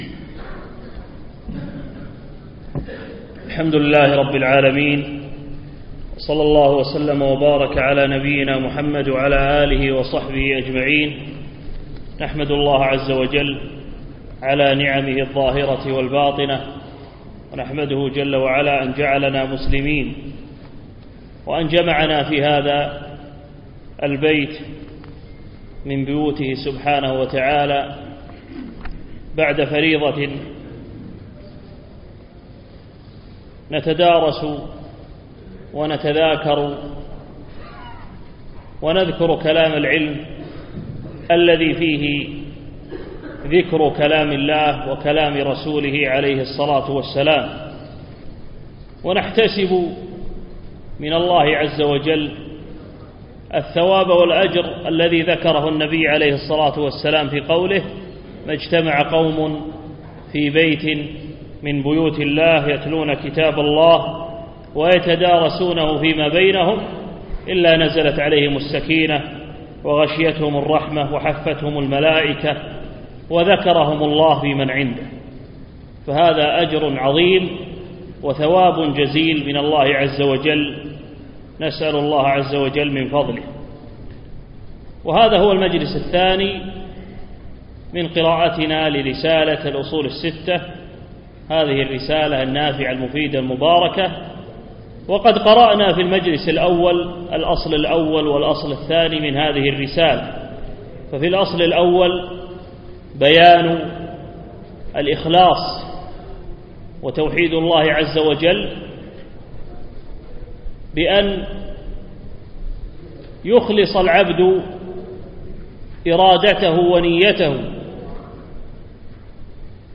يوم الجمعة 19 رجب 1436 الموافق 8 5 2015 بمسجد فهد سند العجمي خيطان